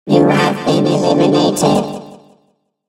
evil_rick_kill_vo_04.ogg